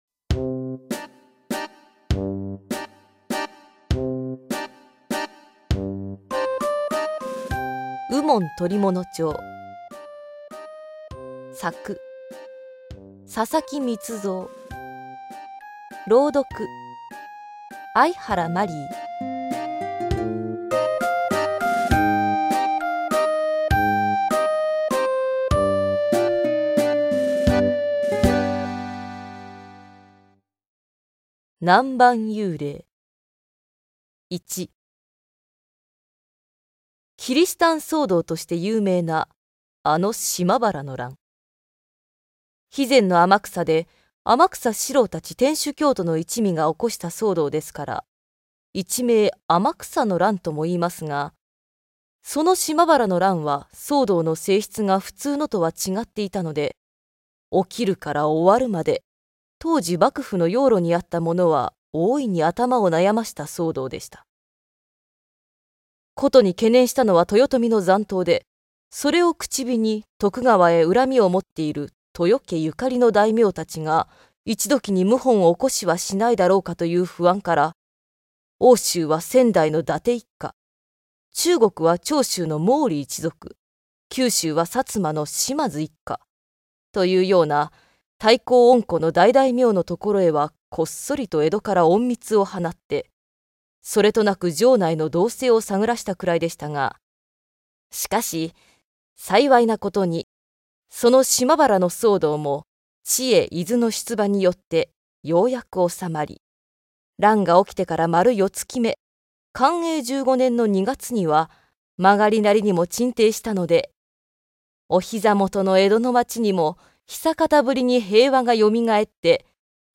[オーディオブック] 右門捕物帖壱「南蛮幽霊」
戦前、嵐寛寿郎主演の映画で一世を風靡した江戸時代を舞台にした推理物「むっつり右門」が、好評にお応えして朗読で再登場です！